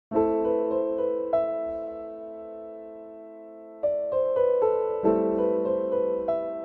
Reactions